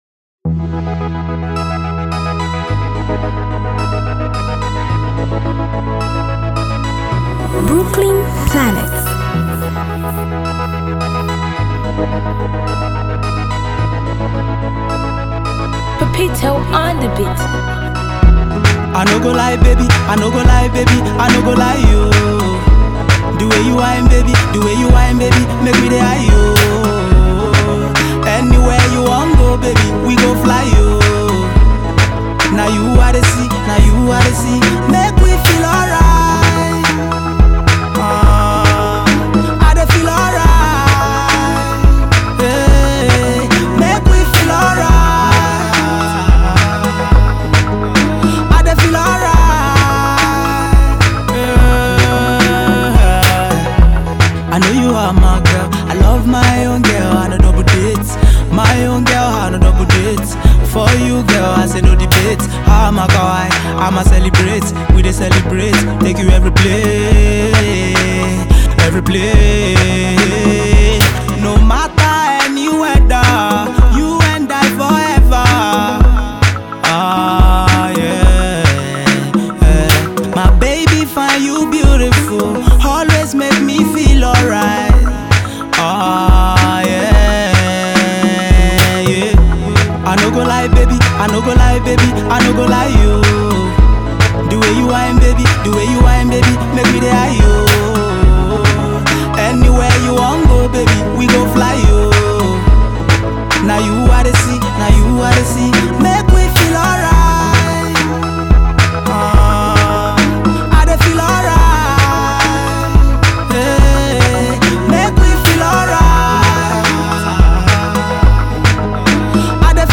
New single
dance hall love tune
The track was mixed and mastered